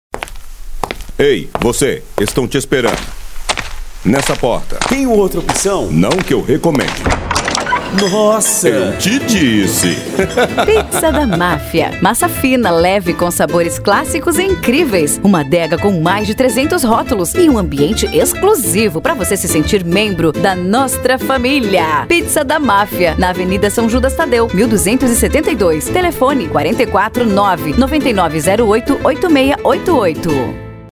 Diálogo: